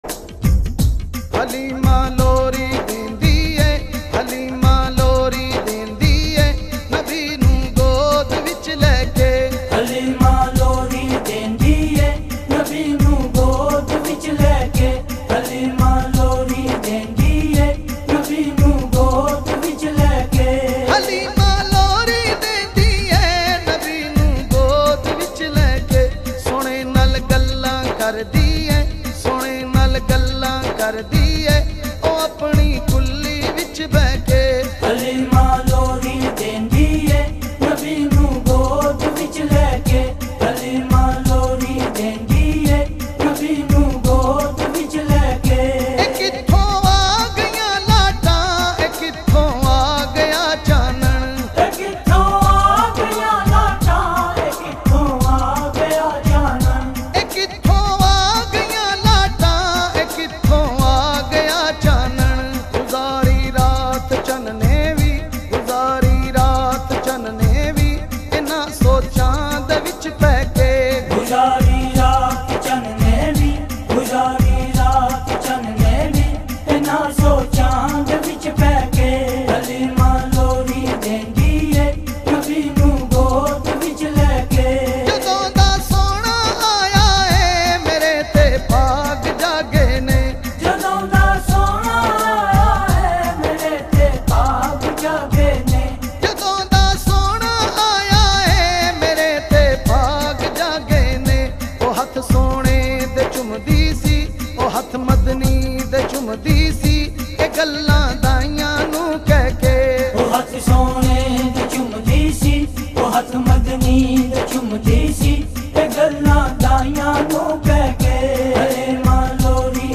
With a very pleasant voice and powerful performance